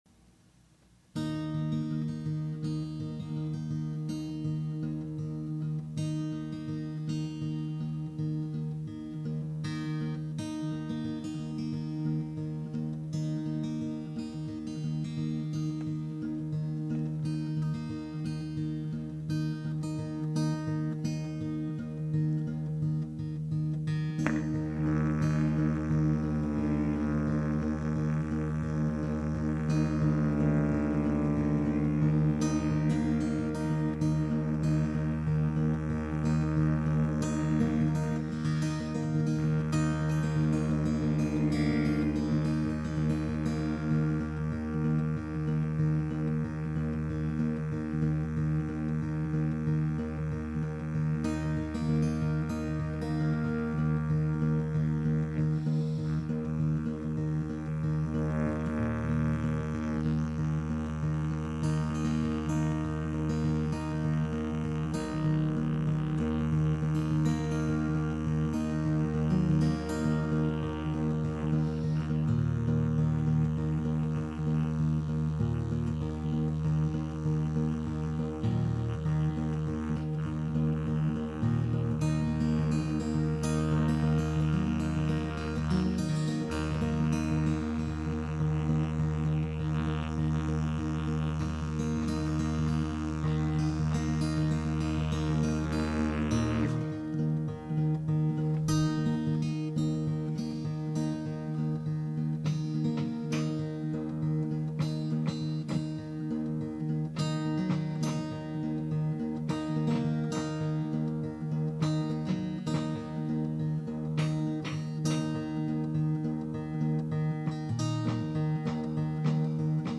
"The Centipede" is all live.